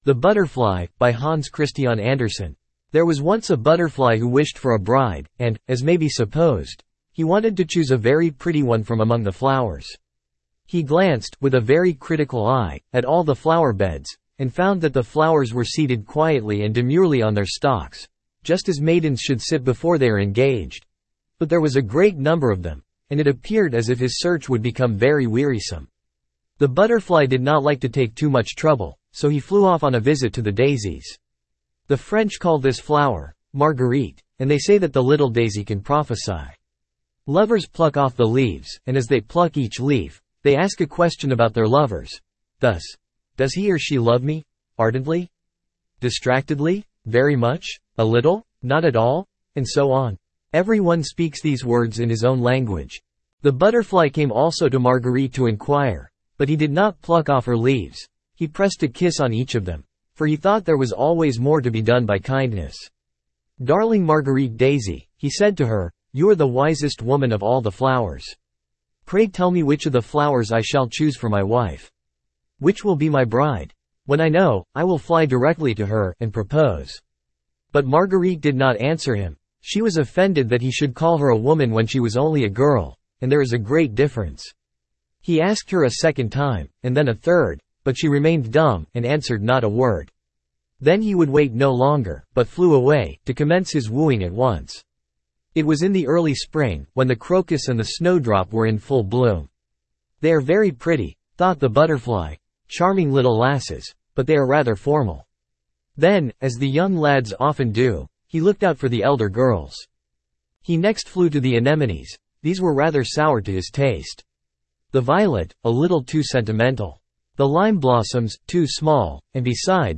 Standard (Male)
the-butterfly-en-US-Standard-D-bdc9bbb8.mp3